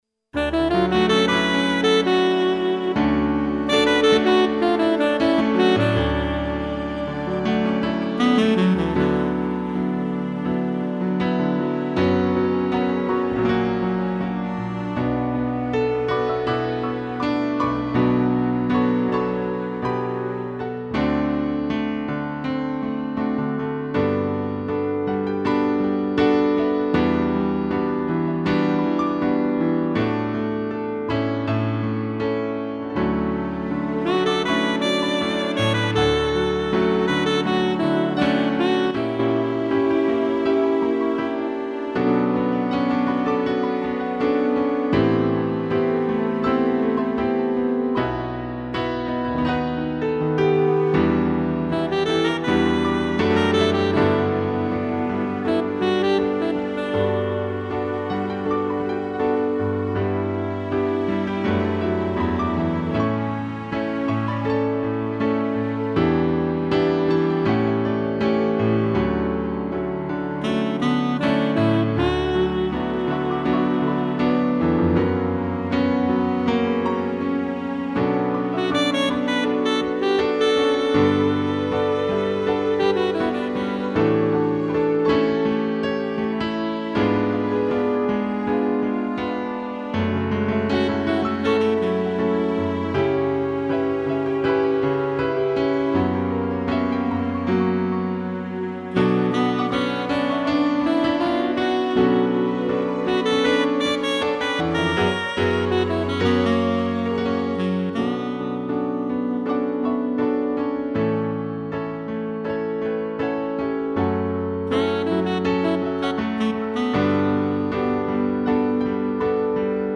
2 pianos, sax e tutti (instrumental)